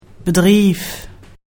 oetspraok / ipa
/bəˈdʀiːf/